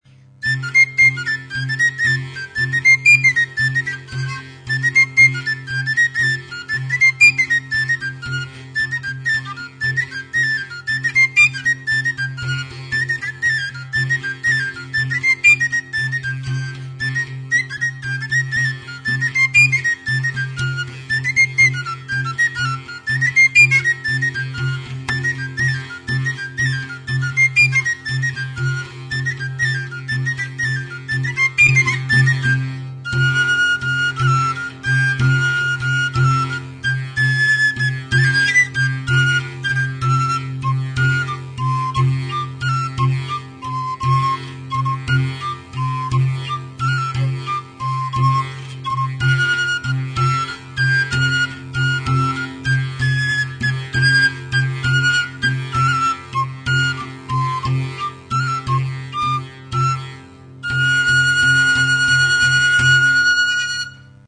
HM udazkeneko kontzertua.
CHICOTEN; SALTERIO
Cordes -> Battues
Sasi errektangularra den zurezko 6 sokazko salterioa da.